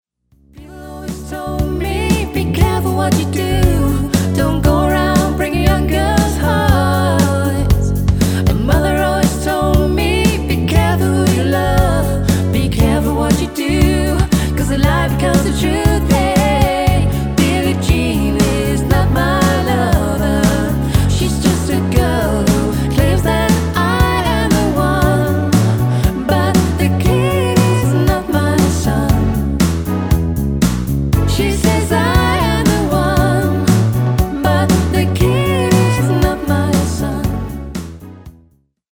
Dansemusik for alle aldre.
• Coverband